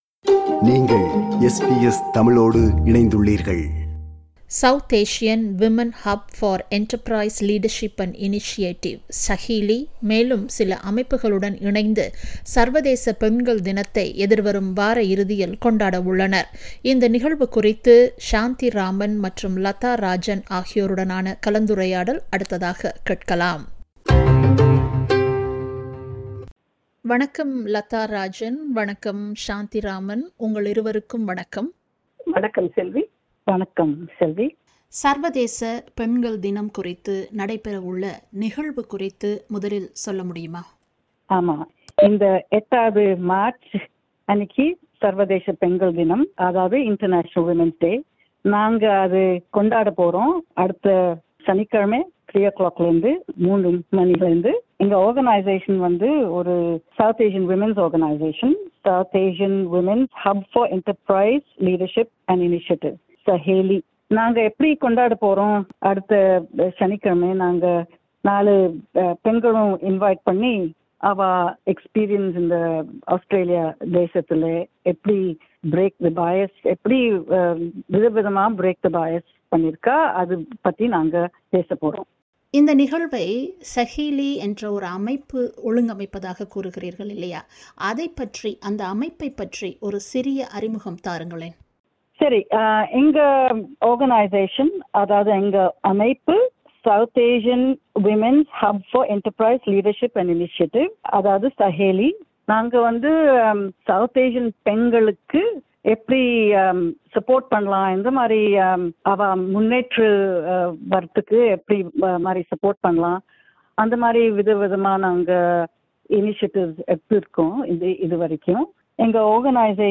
SAHELI along with other organisations is organising a discussion forum about break the bias to celebrate the International Women's Day. This program is a discussion about the event.